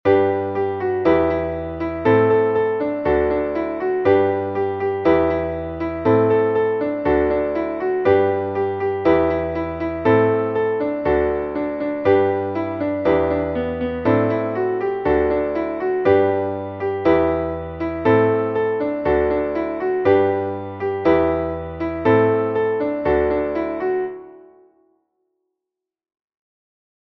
Traditionelles Kinderlied